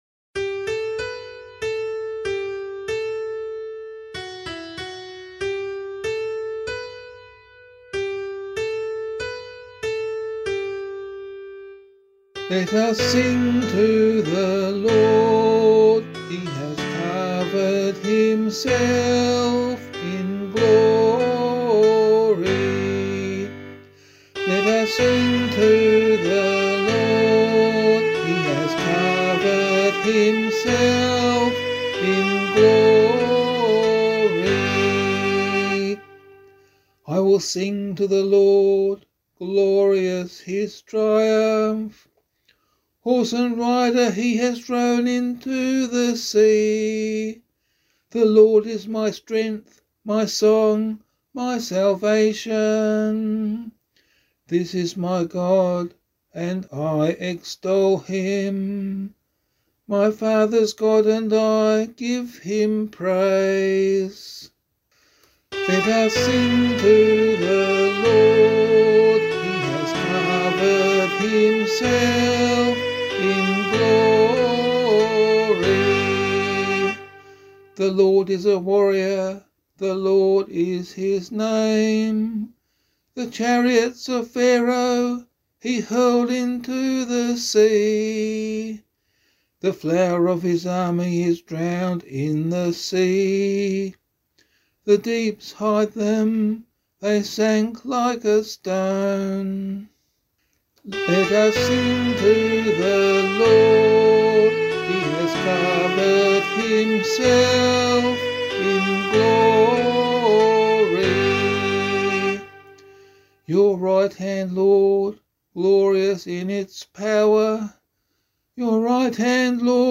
022 Easter Vigil Psalm 3 [LiturgyShare 7 - Oz] - vocal.mp3